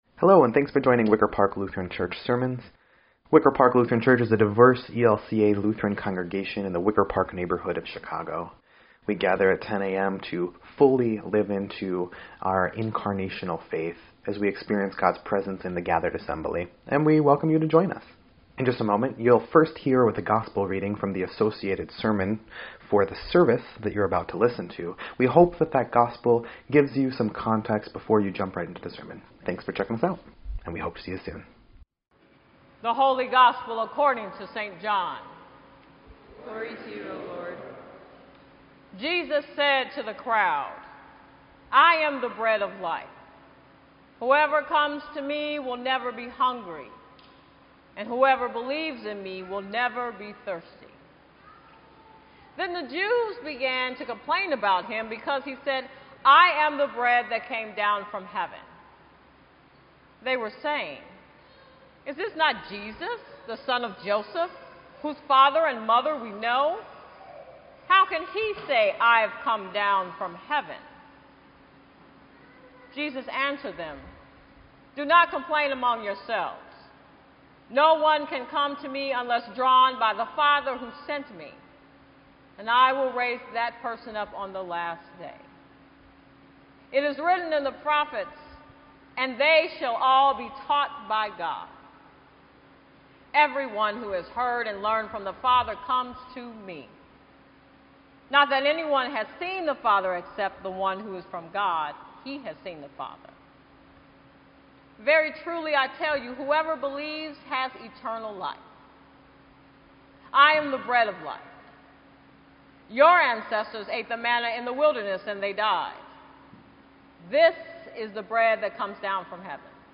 EDIT_Sermon_8_12_18.mp3